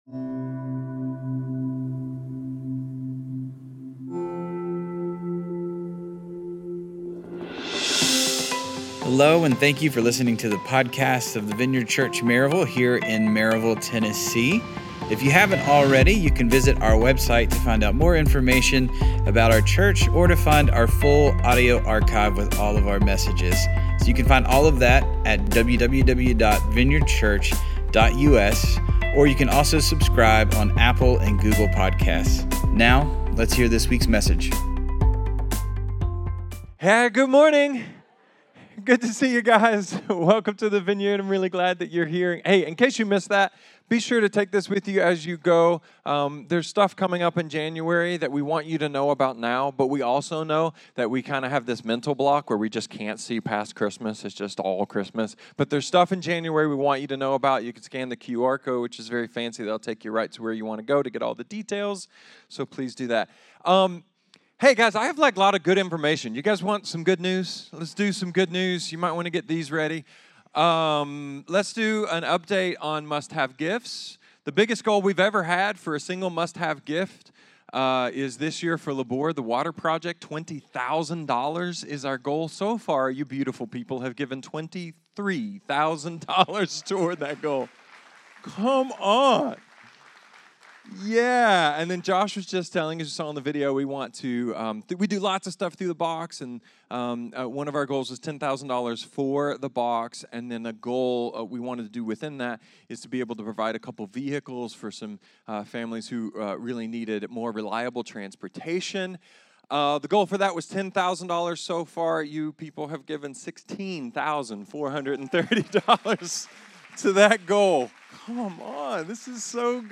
A sermon about what Advent’s all about—and it’s probably not what you think it is!